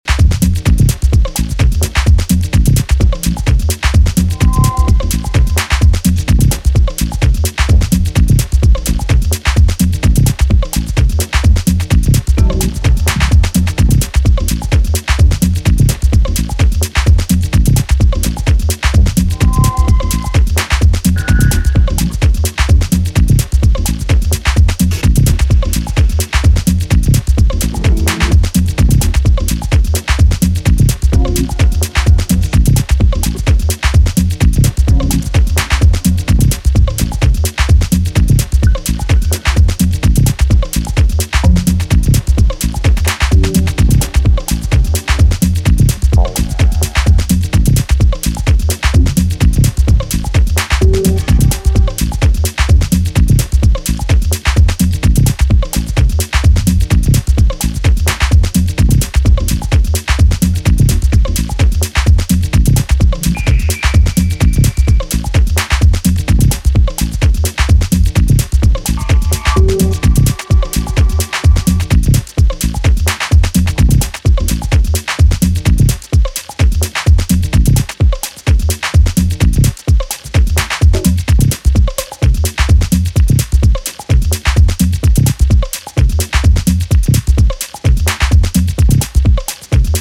奥ゆかしくもサイケデリックな電化トライバル・ハウス